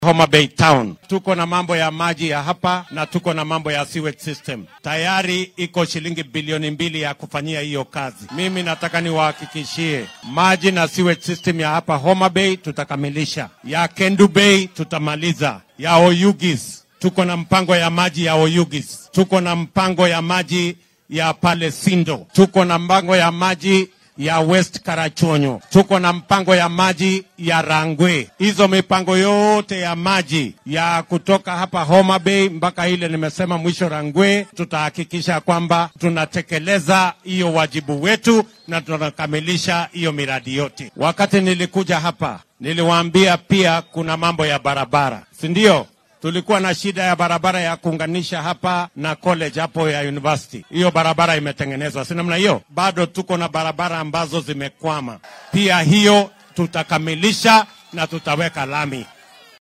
William Ruto oo la hadlayay shacabka ku nool magaalada Homa Bay ayaa sheegay inay jiraan mashaariic wax looga qabanaya adeegyada biyaha ,maareynta wasaqda iyo waddooyinka ee ismaamulkaasi oo lagu qarash gelinaya 2 bilyan oo shilin.